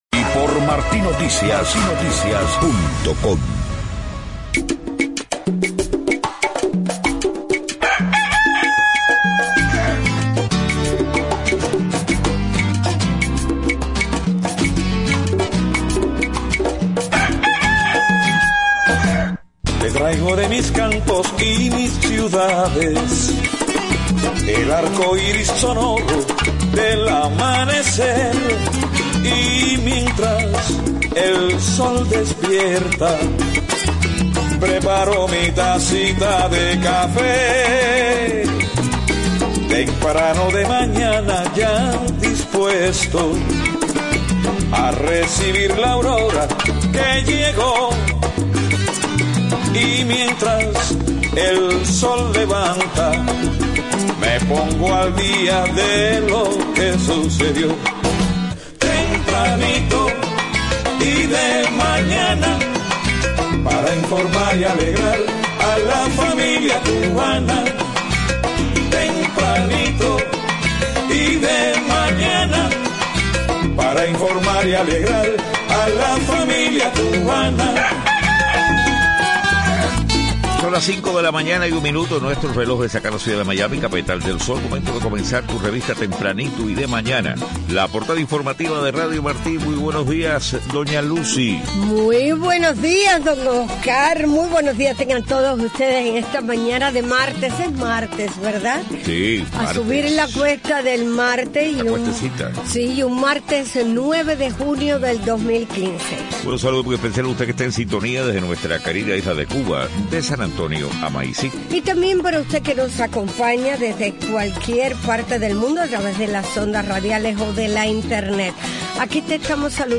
5:00 a.m. Noticias: La artista plástica Tania Bruguera estuvo entre varios activistas que fueron detenidos por varias horas en Cuba este domingo. Ex líder español Felipe Gonzáles se entrevistó en Caracas con opositores del Gobierno chavista.